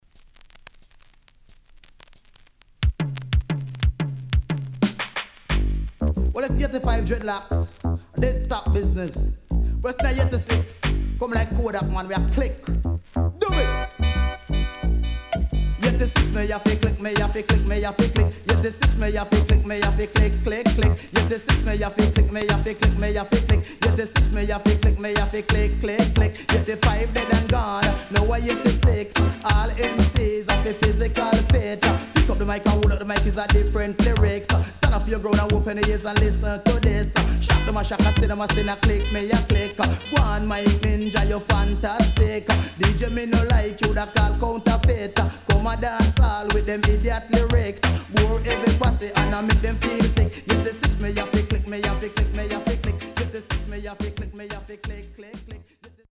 HOME > Back Order [DANCEHALL DISCO45]
Killer & Good Tune
SIDE A:所々チリノイズがあり、少しプチノイズ入ります。